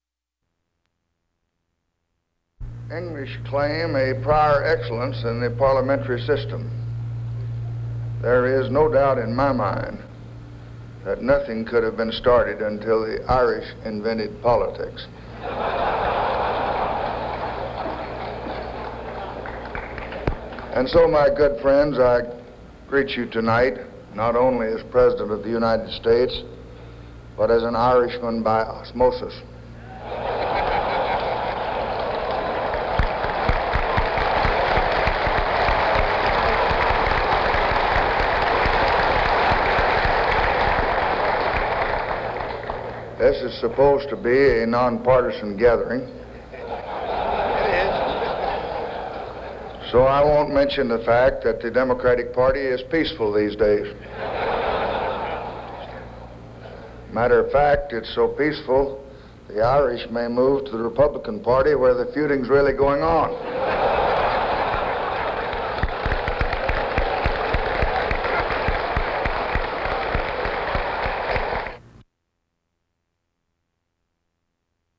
St. Patrick's Day address